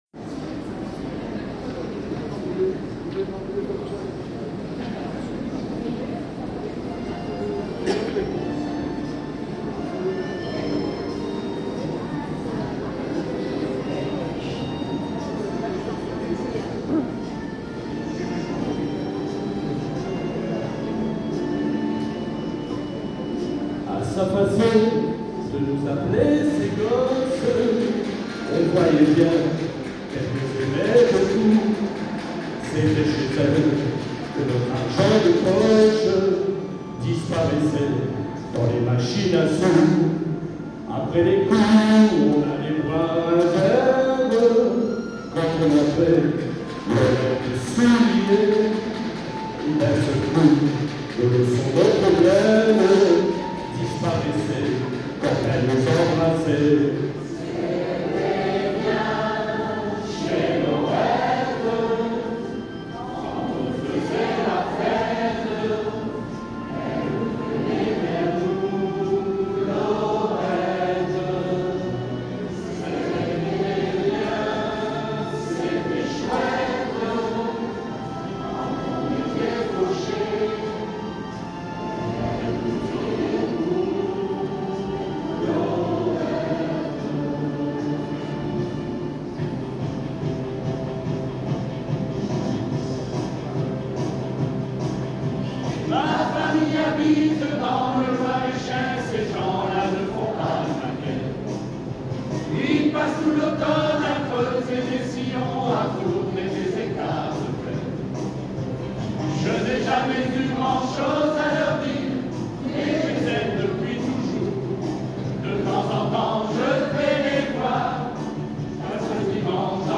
Récital lors du Beaujolais nouveau 21/11/2025